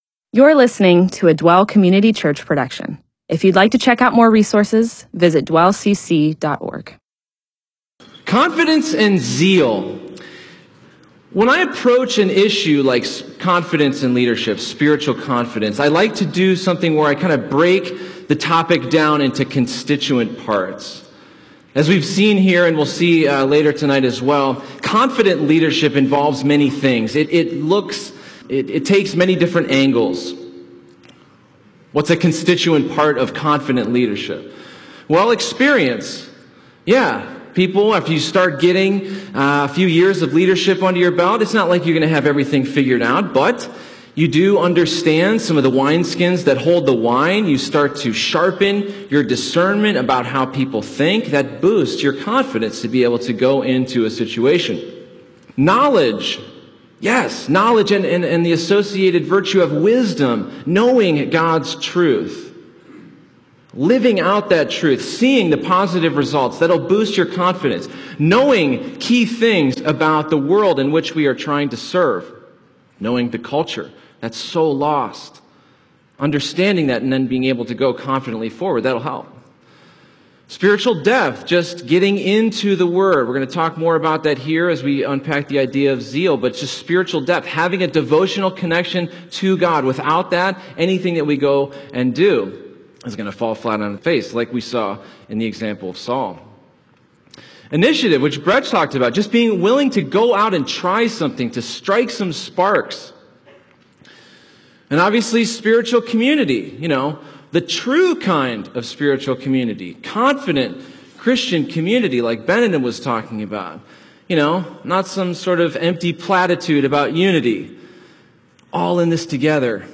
MP4/M4A audio recording of a Bible teaching/sermon/presentation about Romans 12:9-12.